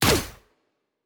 pgs/Assets/Audio/Sci-Fi Sounds/Weapons/Weapon 02 Shoot 2.wav at master
Weapon 02 Shoot 2.wav